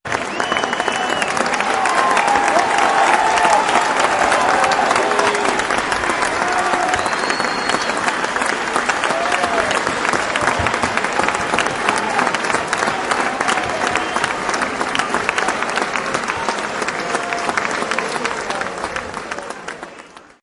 efek_tepuk_tangan.ogg